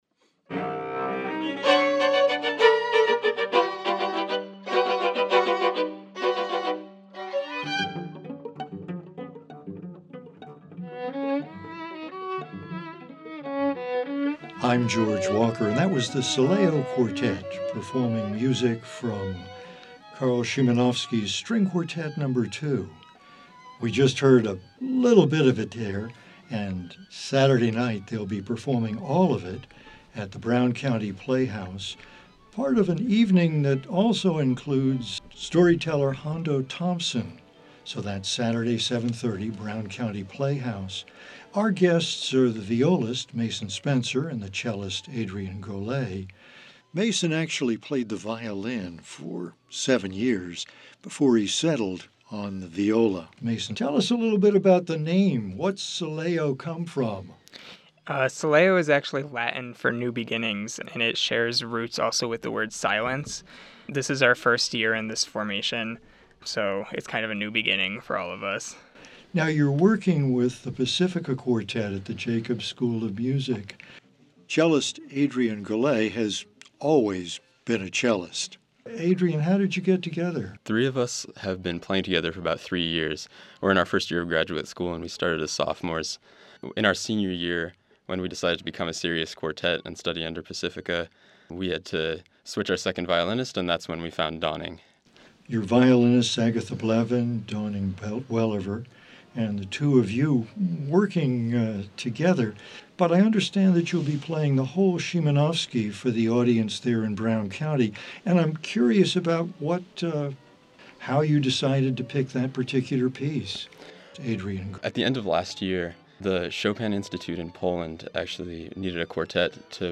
Listen in Popup Download MP3 Comment Sileo Quartet (Sileo String Quartet) A group that seeks to find places for their classical training within larger artistic contexts.